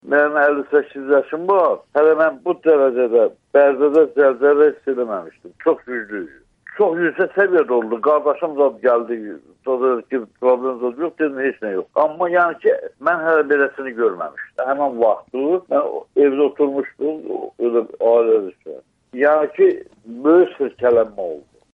Bərdədən kənd sakini zəlzələdən xəbər verir